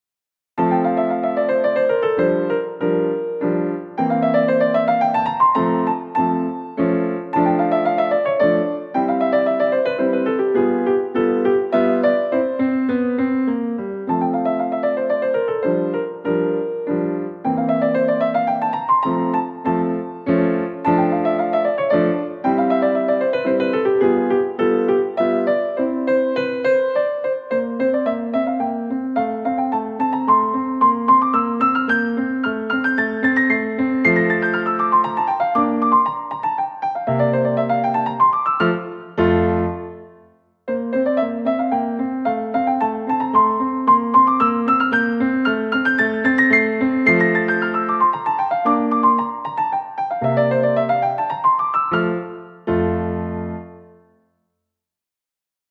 それからブルグミュラー２５の練習曲から５番、のどかで優しい１曲です。